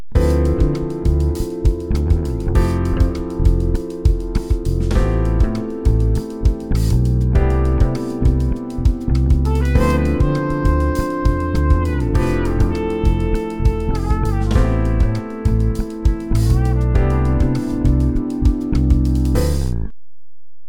• Notice it begins with the chords we practiced on the piano and then just goes up chords in thirds until it gets to C and then repeats
• Look at the melody, notice how all the notes come directly come our D dorian scale we practiced
• Also, the melody is almost completely stepwise, it simply goes down our scale to make a catchy lick